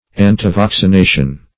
Search Result for " antivaccination" : The Collaborative International Dictionary of English v.0.48: Antivaccination \An`ti*vac`ci*na"tion\, n. Opposition to vaccination.